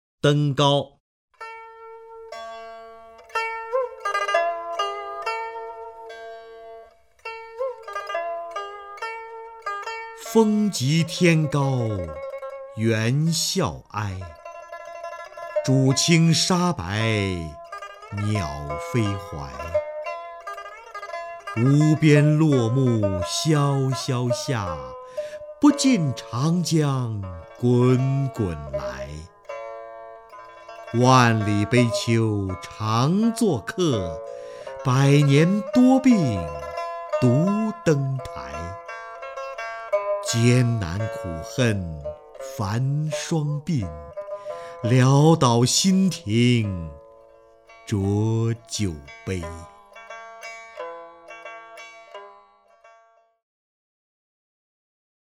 瞿弦和朗诵：《登高》(（唐）杜甫)
名家朗诵欣赏 瞿弦和 目录